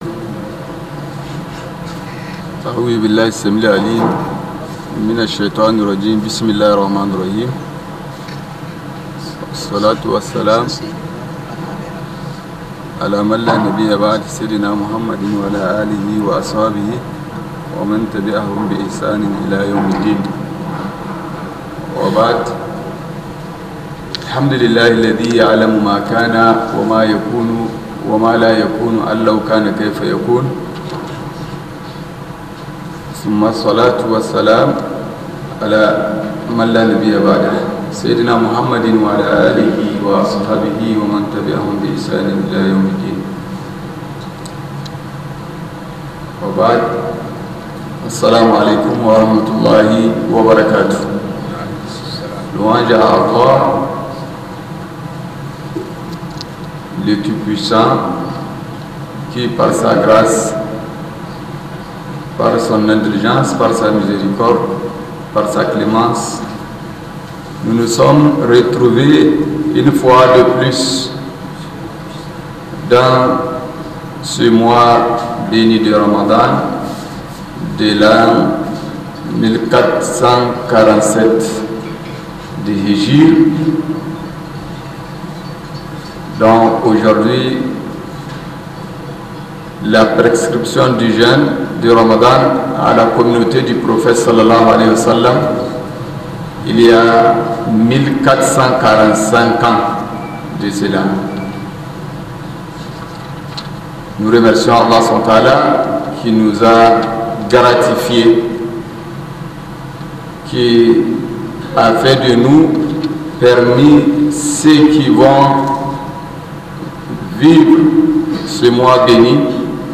Scholar